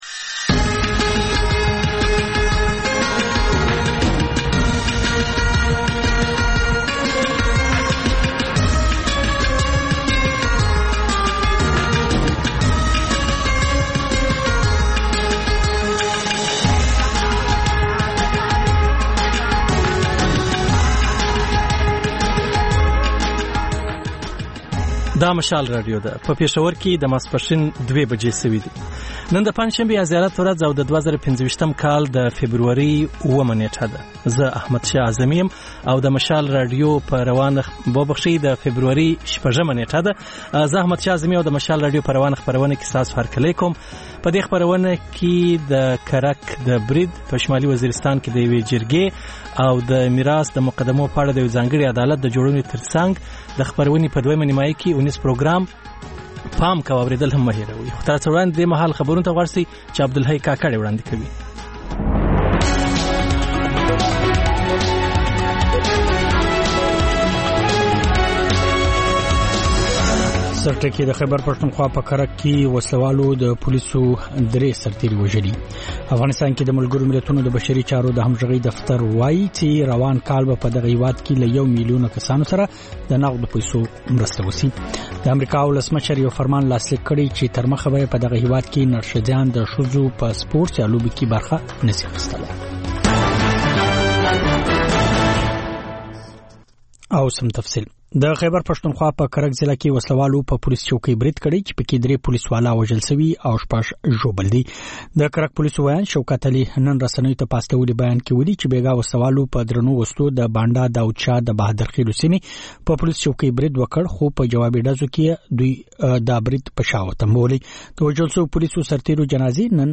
په دې خپرونه کې لومړی خبرونه او بیا ځانګړې خپرونې خپرېږي.